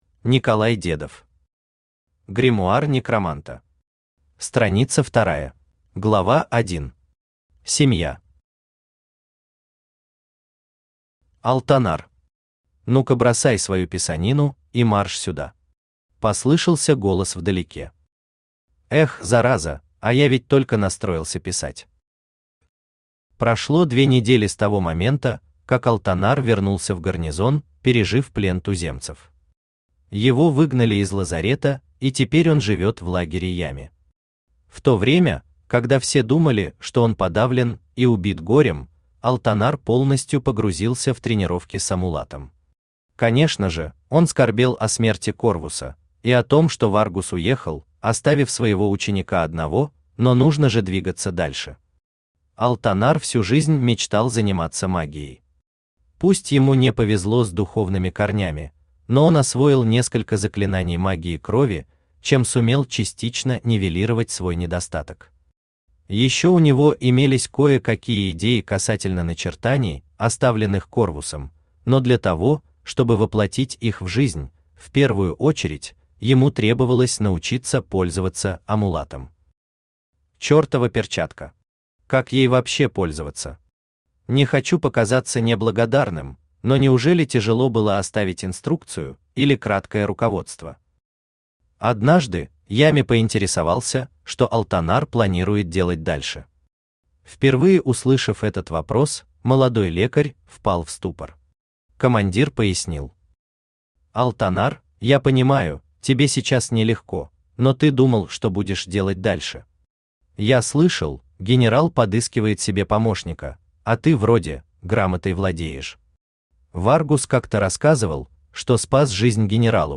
Аудиокнига Гримуар Некроманта.
Aудиокнига Гримуар Некроманта. Страница вторая Автор Николай Дедов Читает аудиокнигу Авточтец ЛитРес.